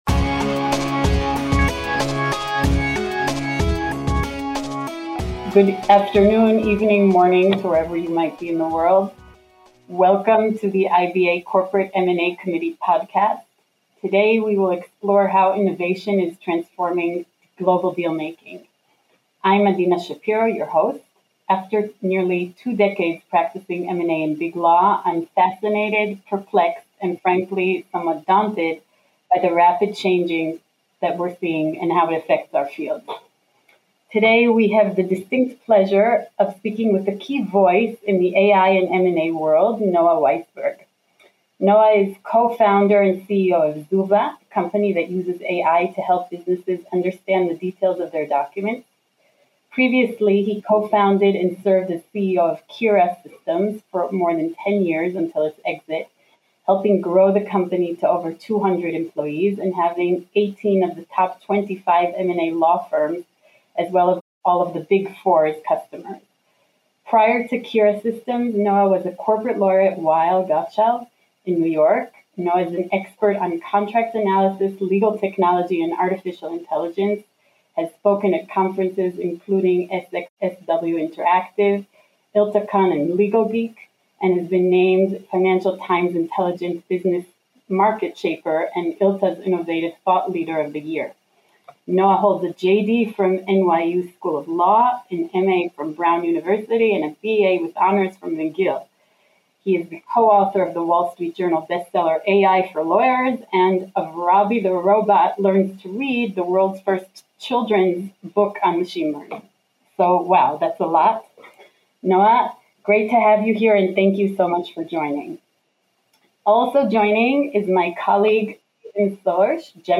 The conversation is moderated